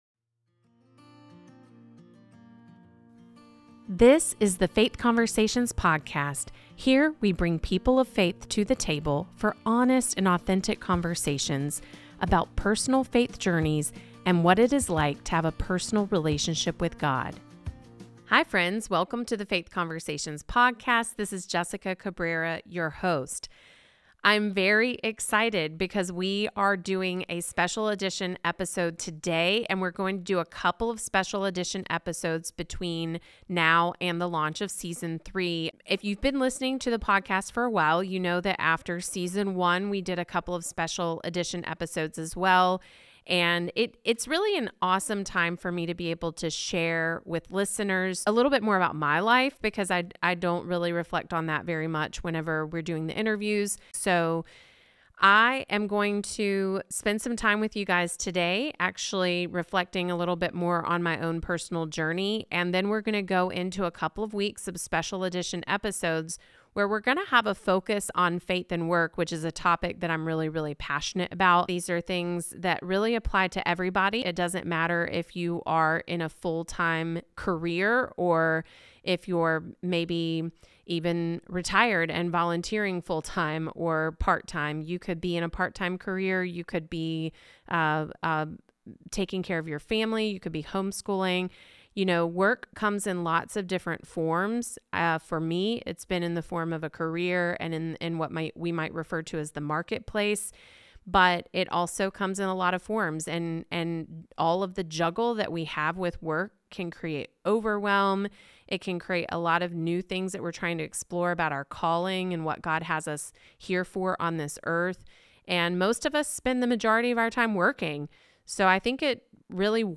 In this special solo edition of the Faith Conversations Podcast